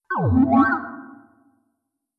spot-activate.wav